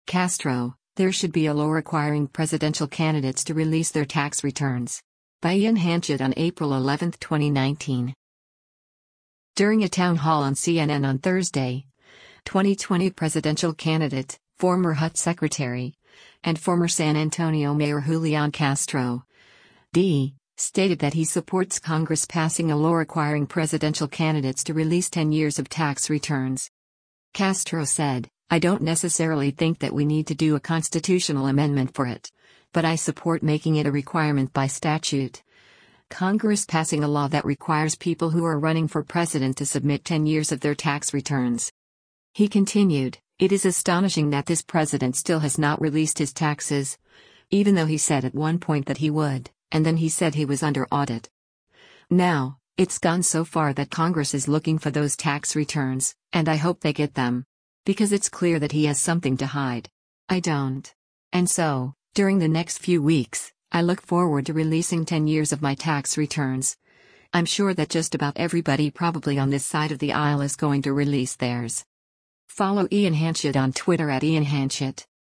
During a town hall on CNN on Thursday, 2020 presidential candidate, former HUD Secretary, and former San Antonio Mayor Julián Castro (D) stated that he supports Congress passing a law requiring presidential candidates to release ten years of tax returns.